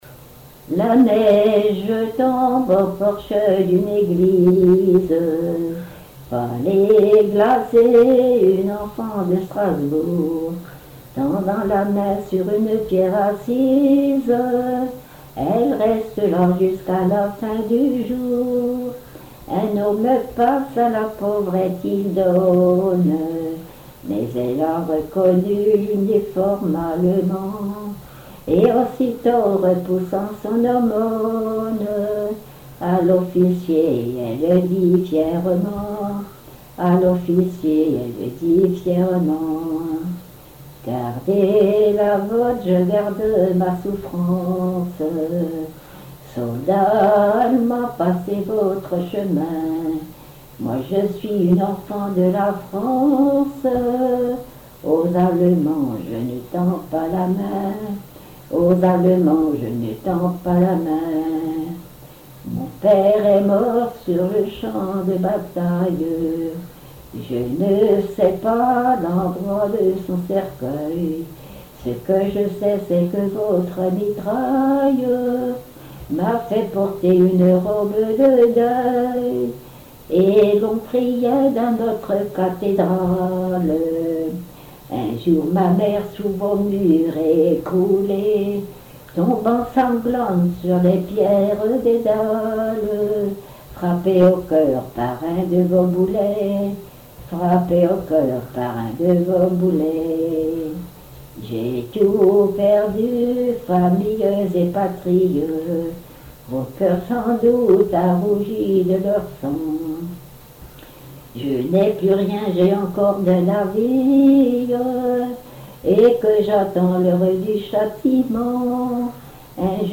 Genre strophique
Enquête Douarnenez en chansons
Pièce musicale inédite